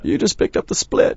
b_pickedupthesplit.wav